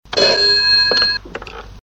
• Old Ringing Phone Sound WITH ECHO.wav
Old_Ringing_Phone_Sound_WITH_ECHO_4wm.wav